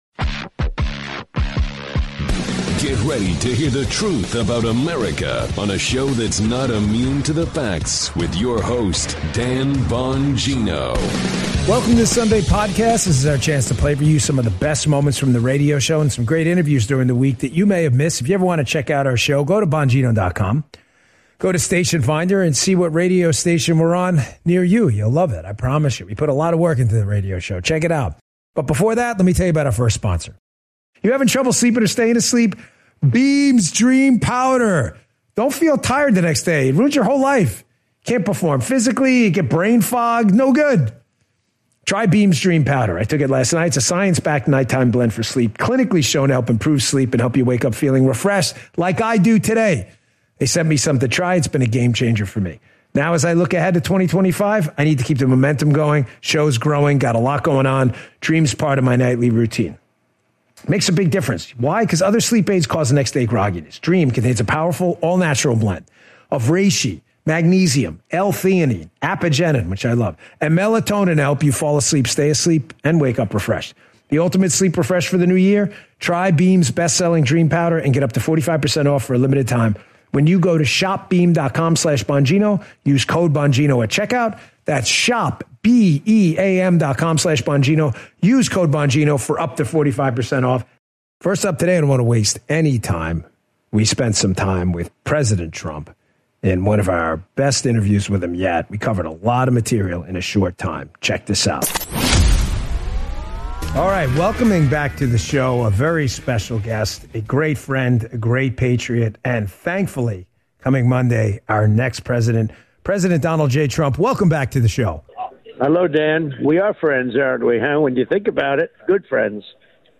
He discusses Beam's Dream Powder, a product that helps with sleep, and chats with President Trump about important issues like offshore drilling and a proposed Israel-Hamas deal. The episode wraps up with a discussion on a big bill that could solve many national problems.